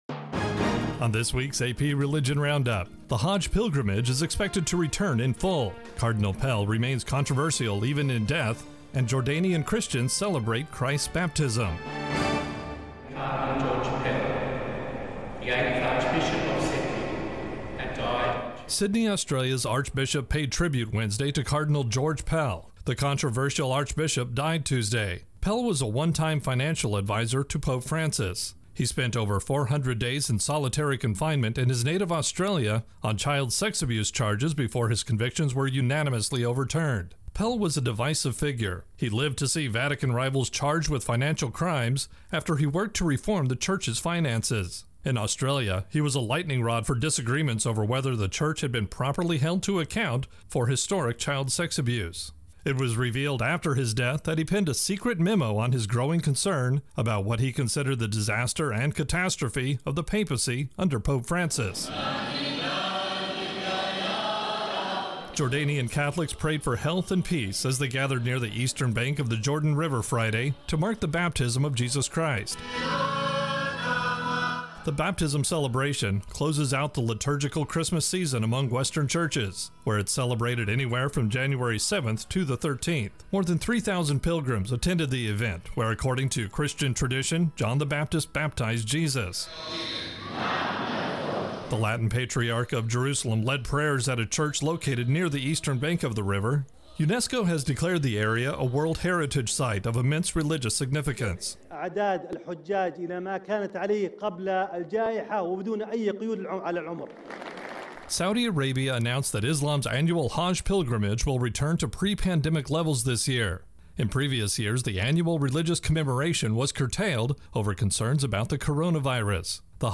Intro and voicer for AP Religion Roundup.